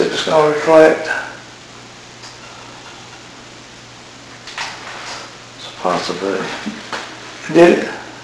The firs tone you will hear is of a male voice sighing and then saying "It's a possibility." this was while I was in the women's rest room.
VOICE SAY'S "IT'S A POSSIBLITY"